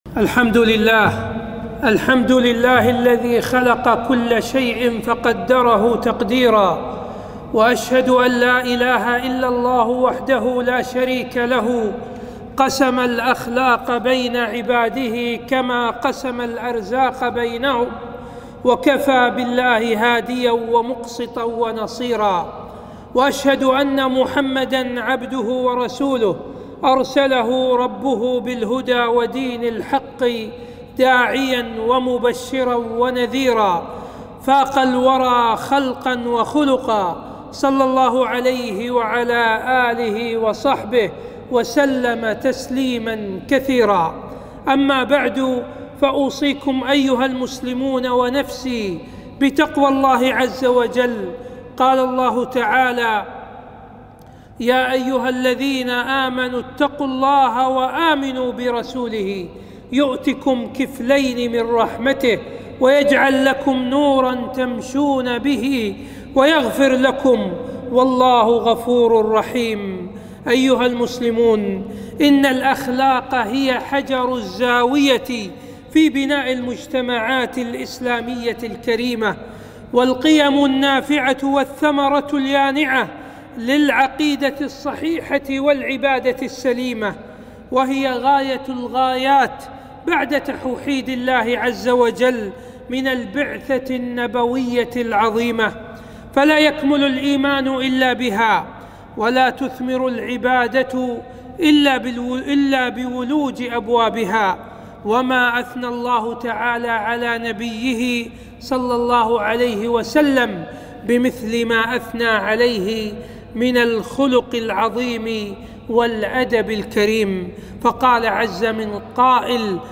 خطبة - مكانة الأخلاق في الإسلام